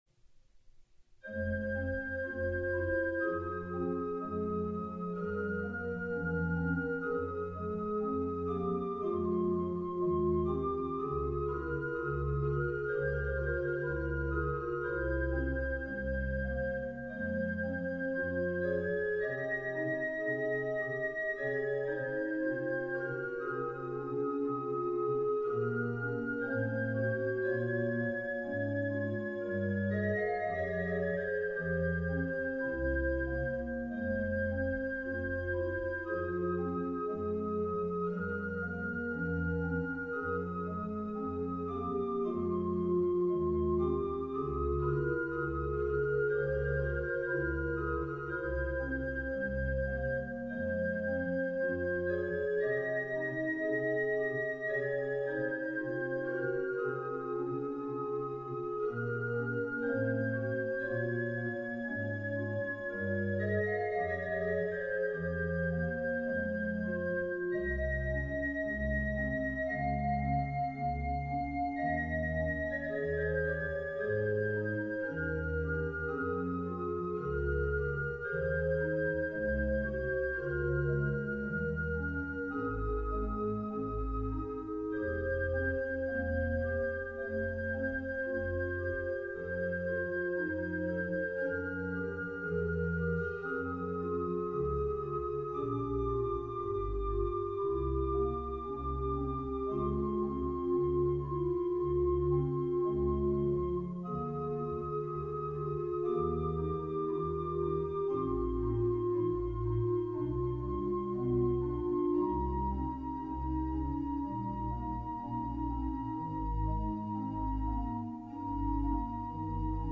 Bij deze orgels zitten de luidsprekers in het bovenblad. Dit zorgt voor een ruimtelijk klankeffect.
Het orgel heeft 9 geluidskanalen + 2 sfeerkanalen 35 registers en 2 klavieren